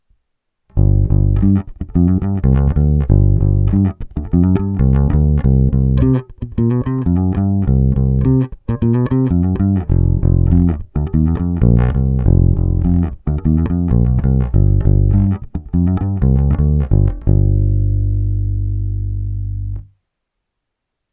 Zvukově je to klasický Precision, i s hlazenkami hraje naprosto parádně, a oproti mnoha jiným Precisionům má tenhle opravdu pevné, vrčivé Éčko.
Nahrávky rovnou do zvukovky, hráno nad snímačem s plně otevřenou tónovou clonou: